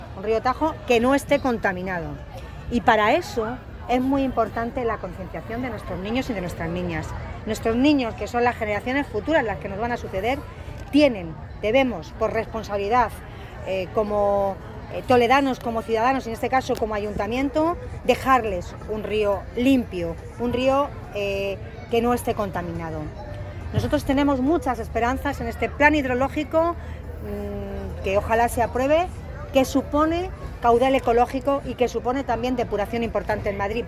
La alcaldesa de Toledo, Milagros Tolón, ha asistido este viernes al acto inaugural de las jornadas reivindicativas a favor del río Tajo.
AUDIOS. Milagros Tolón, alcaldesa de Toledo